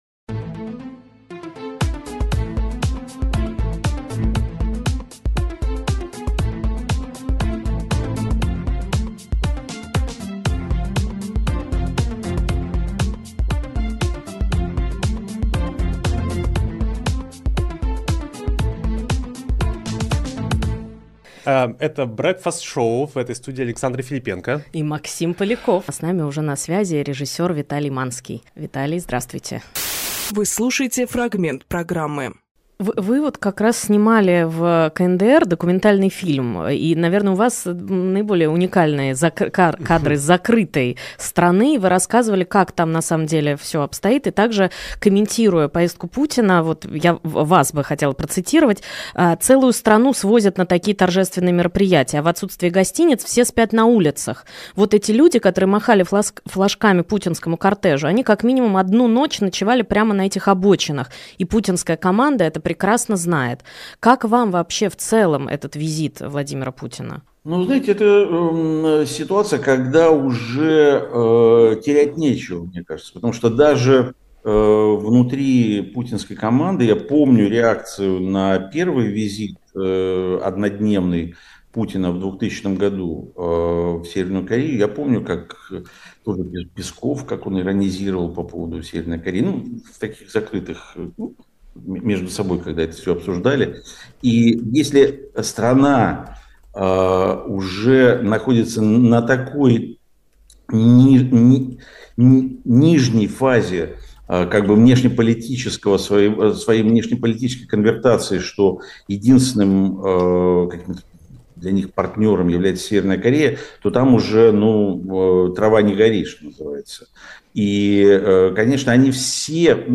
Виталий Манскийрежиссёр-документалист
Фрагмент эфира от 30.06.24